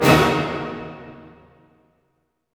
Index of /90_sSampleCDs/Roland LCDP08 Symphony Orchestra/HIT_Dynamic Orch/HIT_Orch Hit Min
HIT ORCHM05R.wav